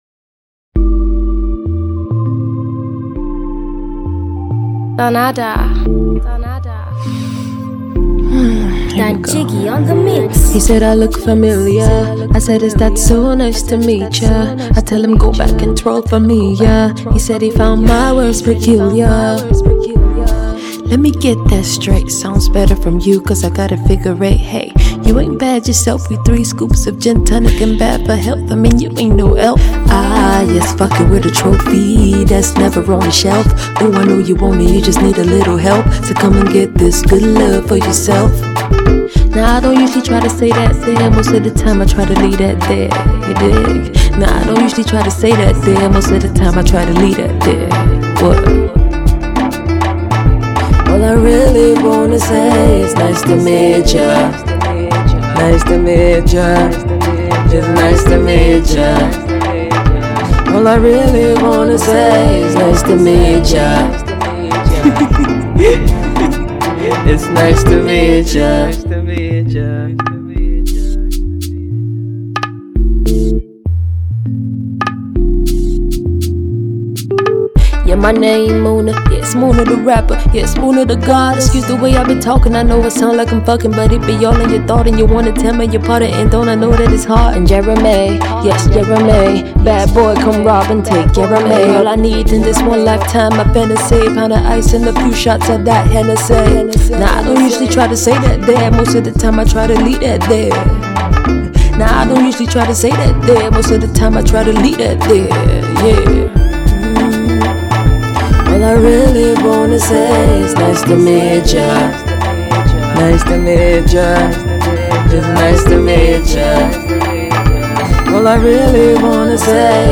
soft core rap